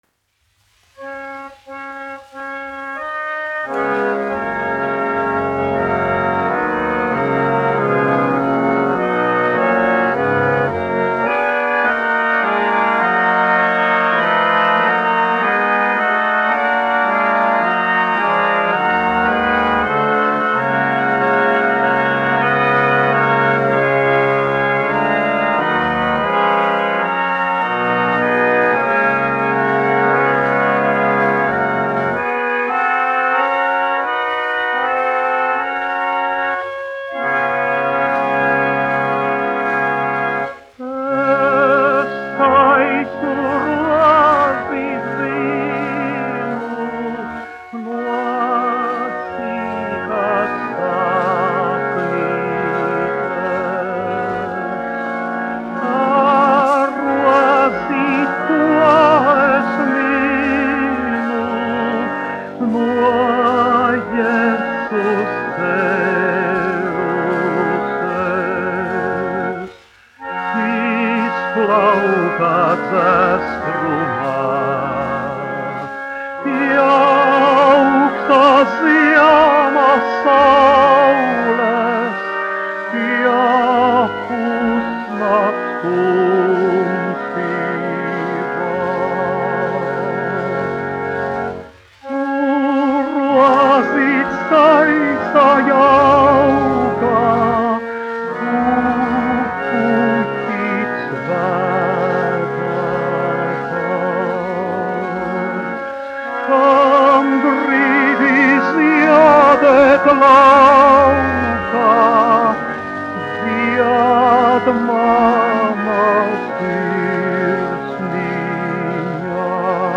Alfrēds Kalniņš, 1879-1951, instrumentālists
1 skpl. : analogs, 78 apgr/min, mono ; 25 cm
Ziemassvētku mūzika
Garīgās dziesmas ar ērģelēm
Latvijas vēsturiskie šellaka skaņuplašu ieraksti (Kolekcija)